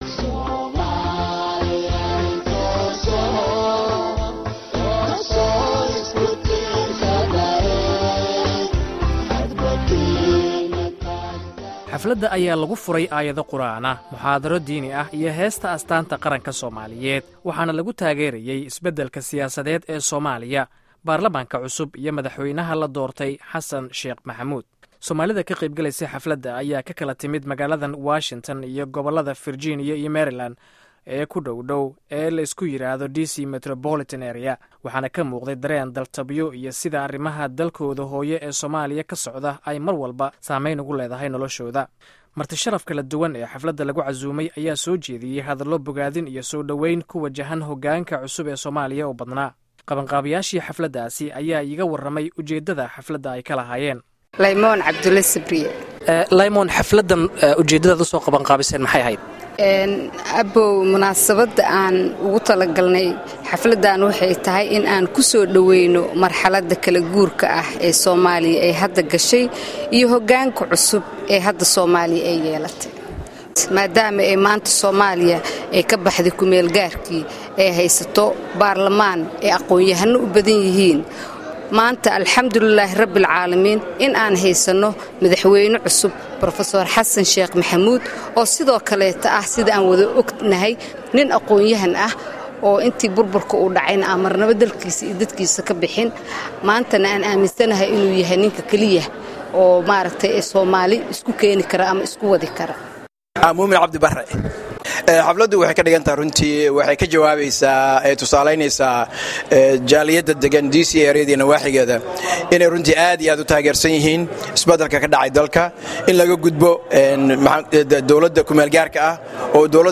Codka Warbixinta Jaaliyadda Somalida ee DC area halkan ka dhageyso